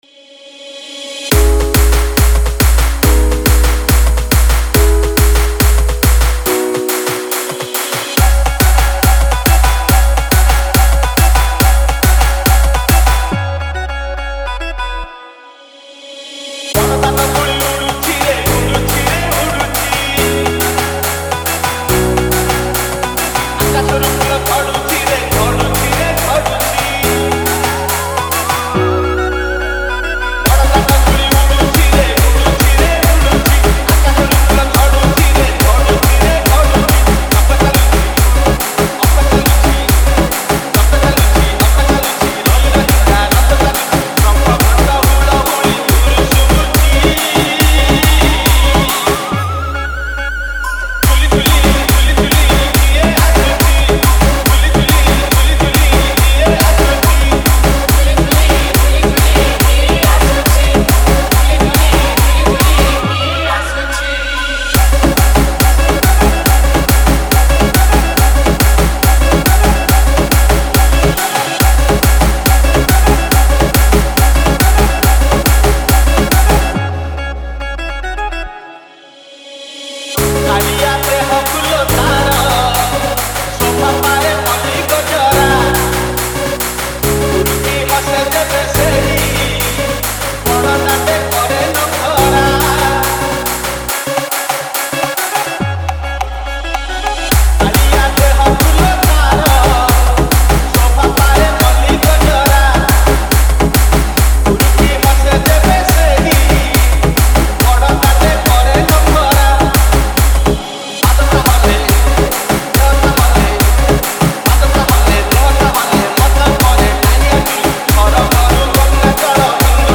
Bhajan Dj Song Collection 2021 Songs Download